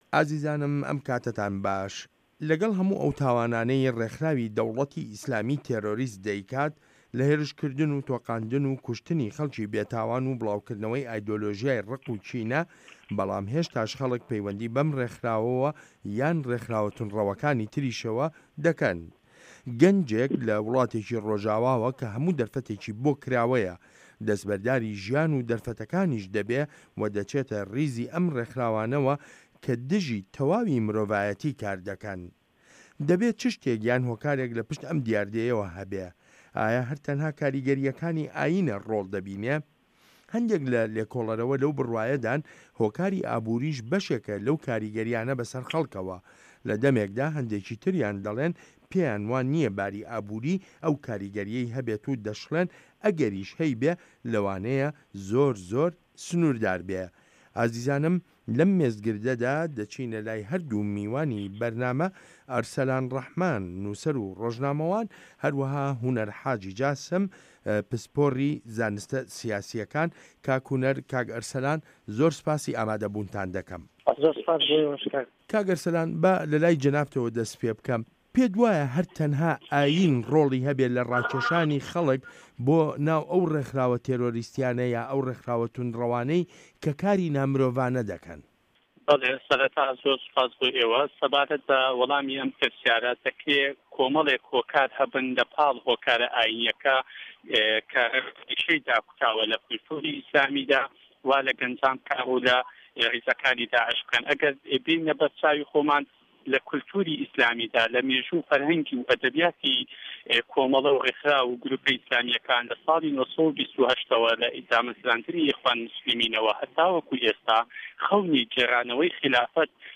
مێزگرد: ده‌وڵه‌تی ئیسلامی و میکانیزمه‌کانی ڕاکێشانی خه‌ڵک